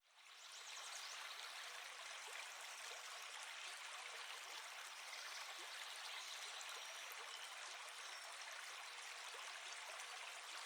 fx_water_1